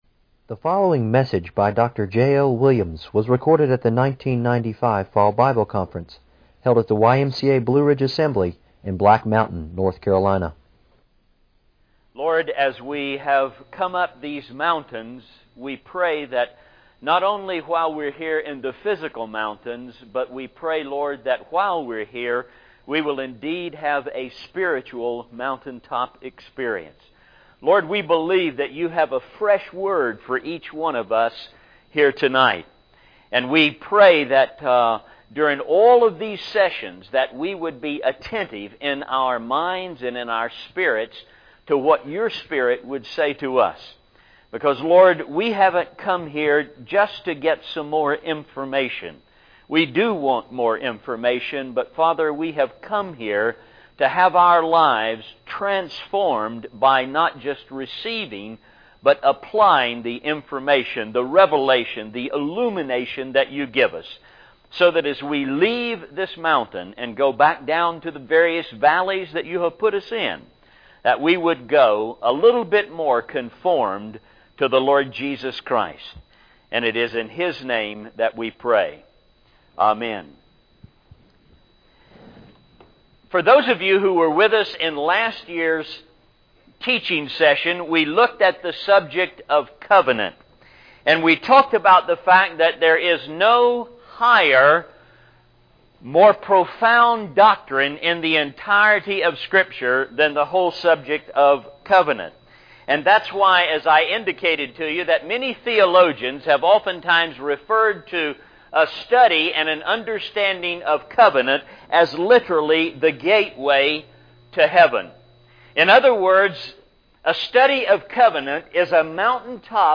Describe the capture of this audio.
This message was delivered at the 1995 Fall Bible Conference held at the YMCA Blue Ridge Assembly in Black Mountain, North Carolina.